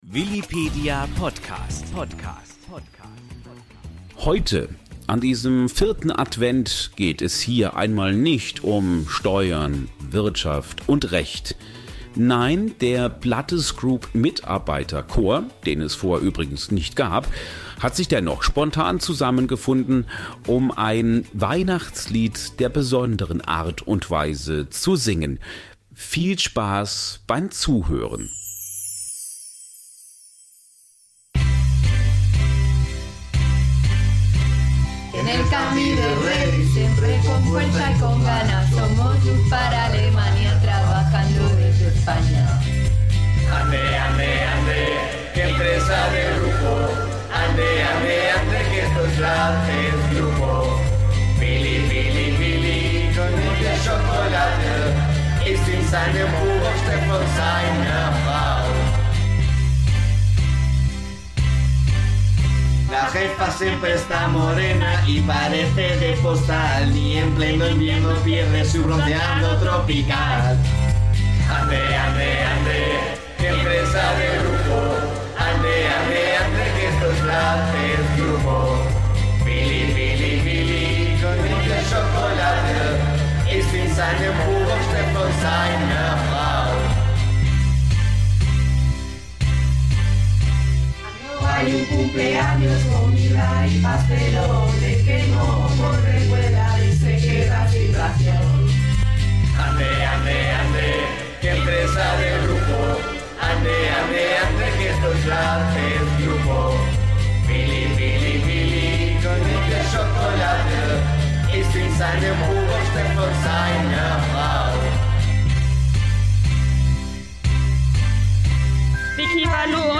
... mit einem ganz besonderes Weihnachtslied Heute an diesem vierten Advent, geht es hier einmal nicht Steuern, Wirtschaft und Recht. Nein, der PlattesGroup-Mitarbeiter-Chor, den es vorher übrigens nicht gab, hat sich spontan zusammengefunden, um ein Weihnachtslied der besonderen Art und Weise zu singen.